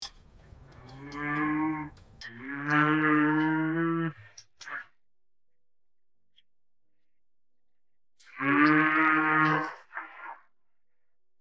Download Cow sound effect for free.
Cow